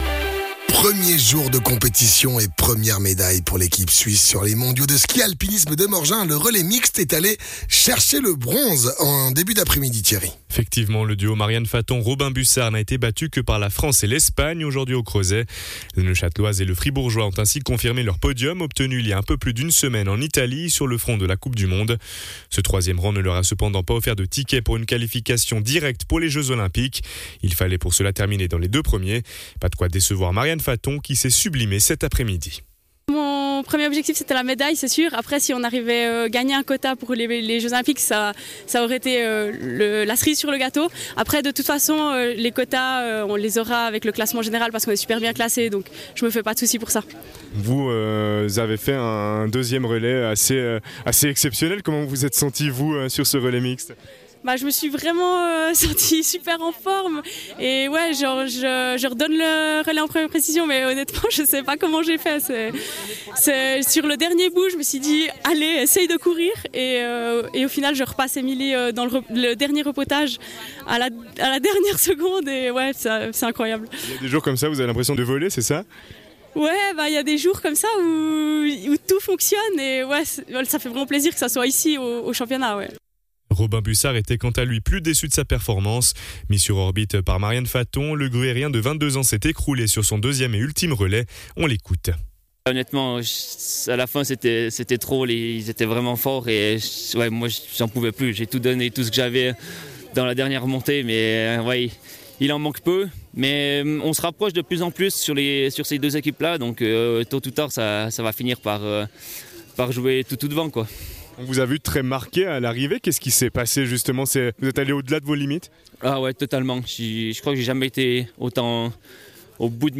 athlètes